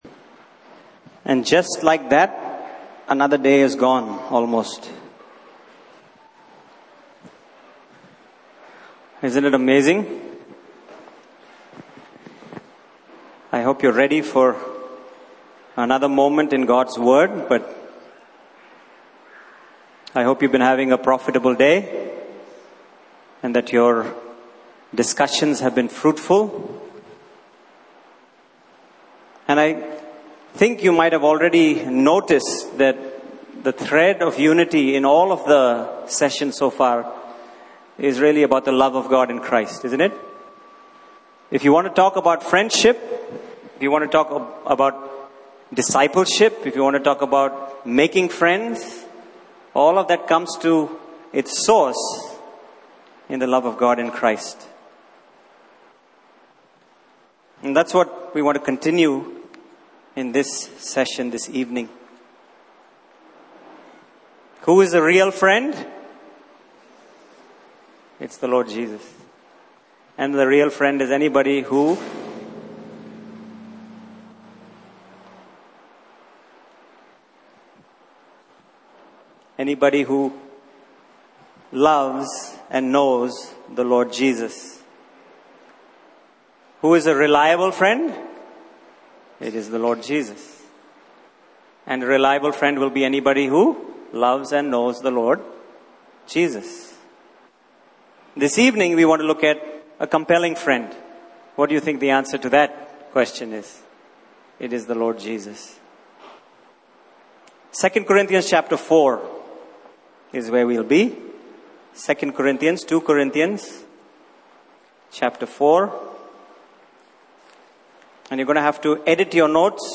Passage: 2 Corinthians 5:14-15 Service Type: Main Session